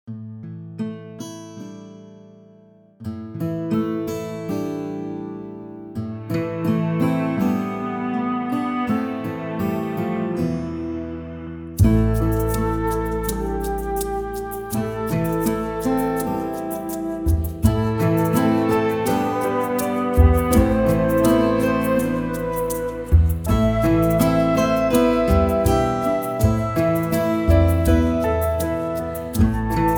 Besetzung: Sopranblockflöte